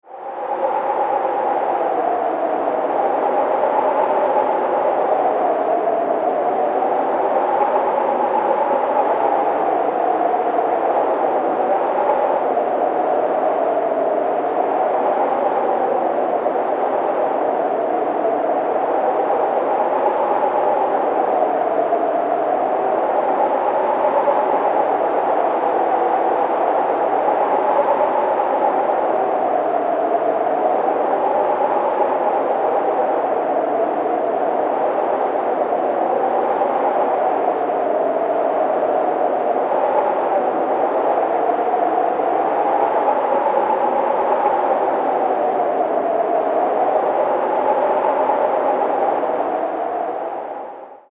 Strong Wind Blowing Sound Effect
A strong winter or desert wind blows eerily, creating a chilling and haunting atmosphere.
Use realistic gusts and howling wind to add dramatic tension to any project.
Strong-wind-blowing-sound-effect.mp3